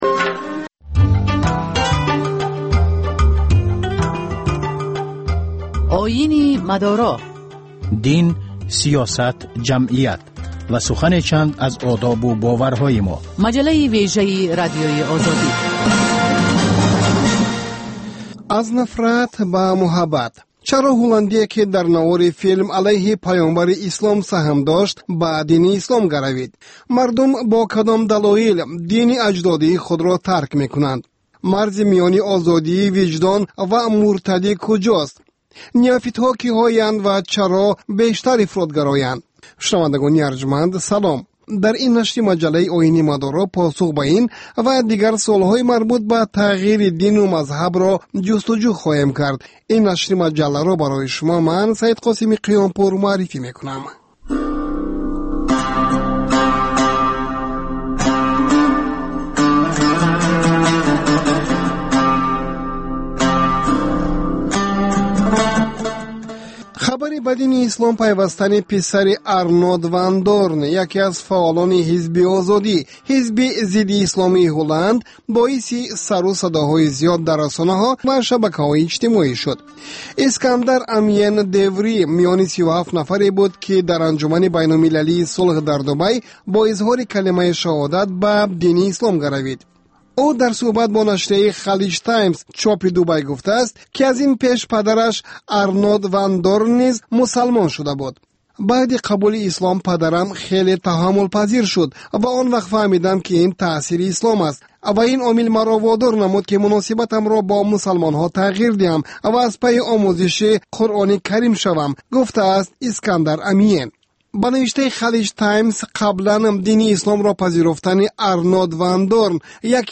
Гузориш, мусоҳиба, сӯҳбатҳои мизи гирд дар бораи муносибати давлат ва дин.